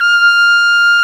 Index of /90_sSampleCDs/Roland L-CDX-03 Disk 2/BRS_Piccolo Tpt/BRS_Picc.Tp 2 St